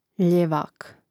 ljèvāk ljevak